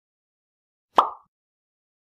pop for aimlab
pop-sound-effect-1.mp3